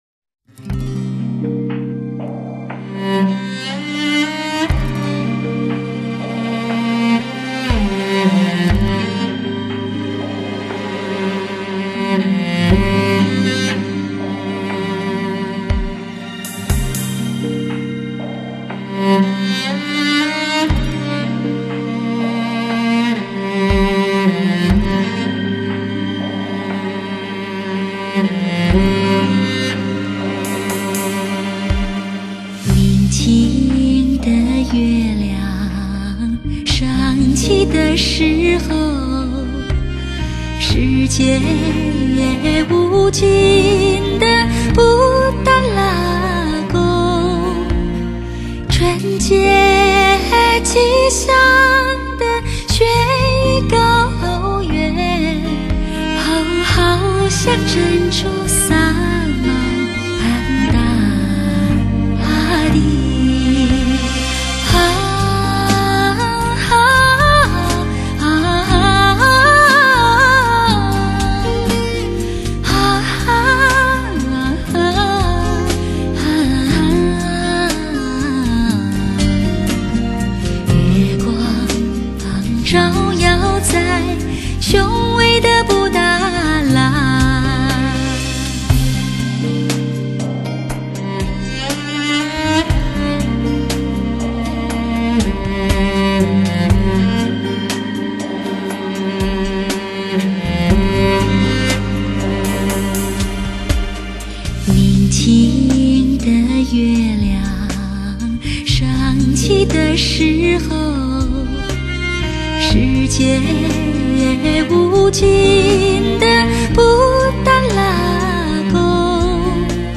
流行元素融入经典作品，传统金曲焕发鲜活生命。